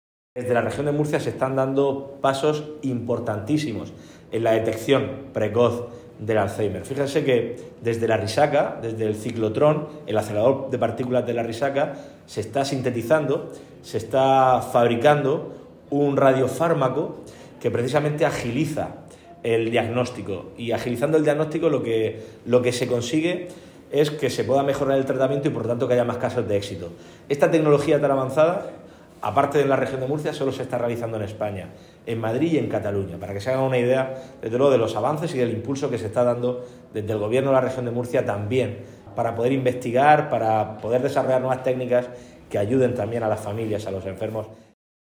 Sonido/ Declaraciones del presidente del Gobierno de la Región de Murcia, Fernando López Miras, sobre los avances que está protagonizando el Hospital de La Arrixaca en la identificación precoz de dicha enfermedad.